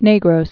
(nāgrōs, nĕ-)